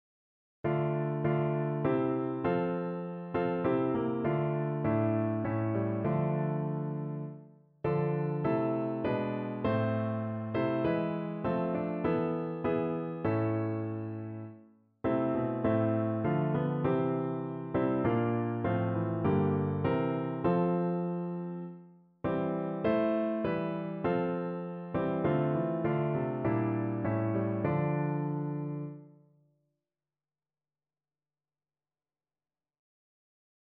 No parts available for this pieces as it is for solo piano.
F major (Sounding Pitch) (View more F major Music for Piano )
4/4 (View more 4/4 Music)
Piano  (View more Intermediate Piano Music)
Classical (View more Classical Piano Music)
o_lord_my_god_PNO.mp3